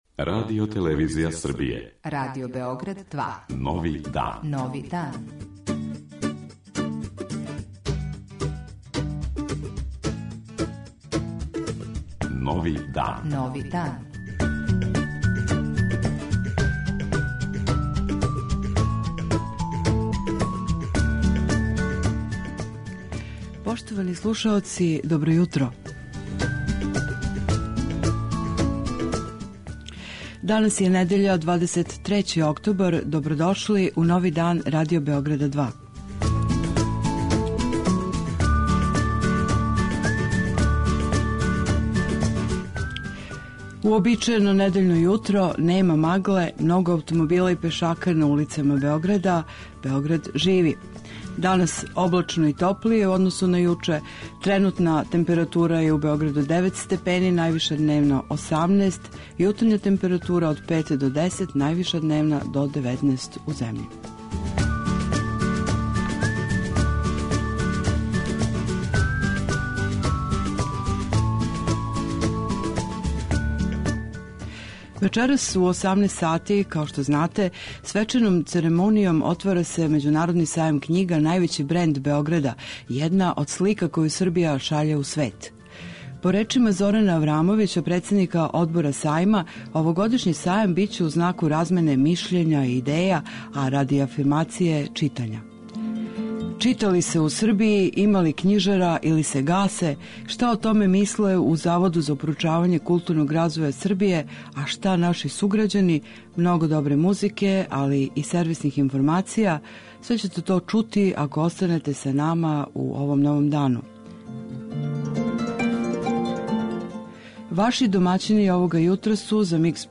Уз вести, сервисне информације о времену и стању на путевима, и много добре музике, надамо се да ће вас наша емисија лагано и опуштено увести у недељно јутро и нови дан.